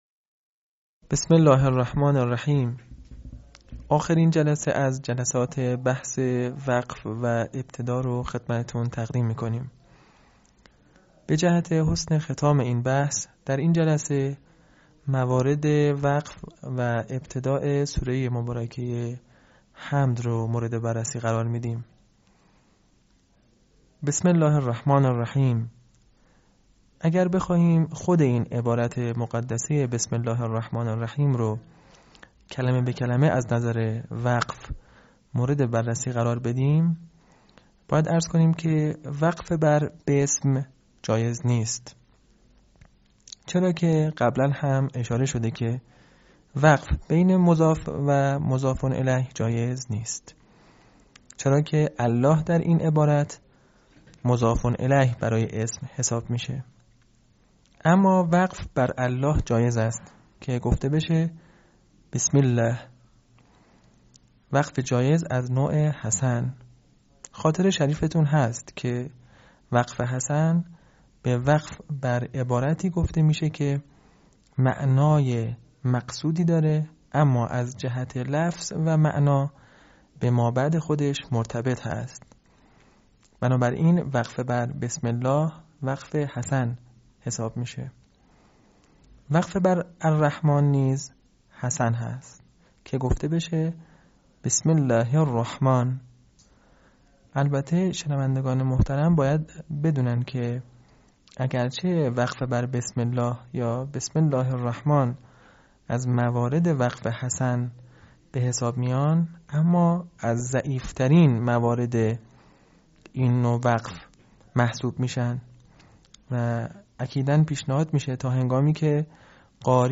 آموزش وقف و ابتدا